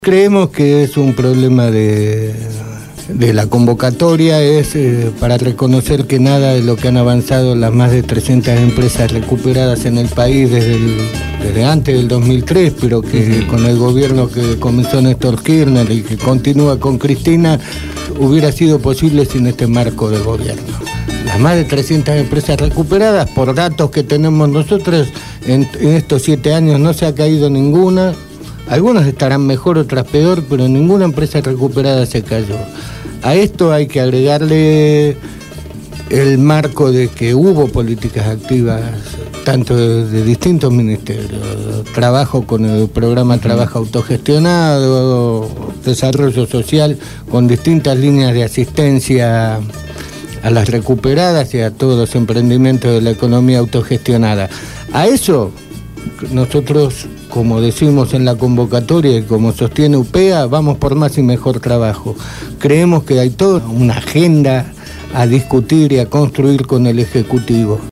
Entrevista
en los estudios de Radio Gráfica FM 89.3 con motivo del encuentro de trabajadores de empresas autogestionadas que se realizado el viernes 14 de octubre en la Cooperativa Gráfica Patricios.